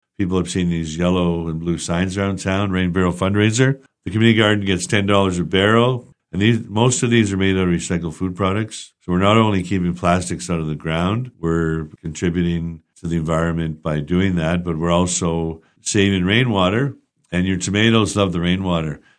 As a means of  partially providing these funds the 4th annual Rain Barrel Sale is kicking off this spring, and Deputy mayor Brian Abdallah helps to explain what those blue and yellow signs we’ve been seeing around town are all about: